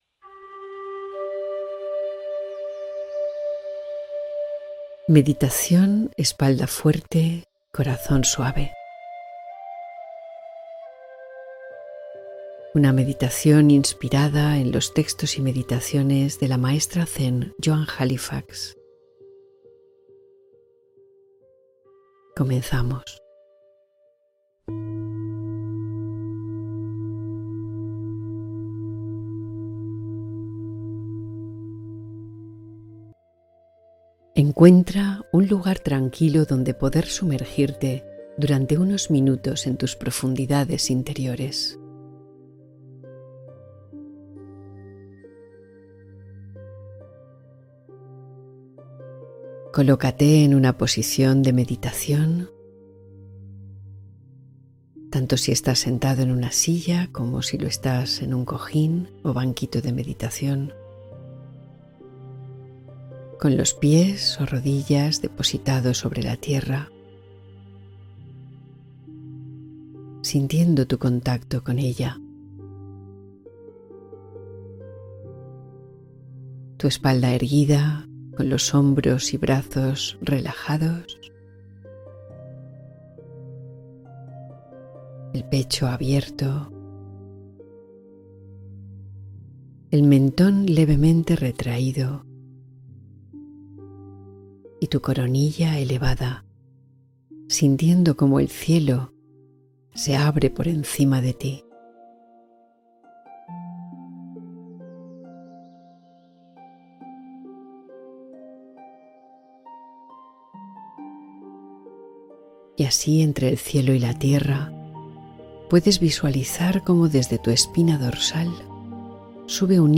Espalda fuerte, corazón suave: meditación guiada para equilibrio emocional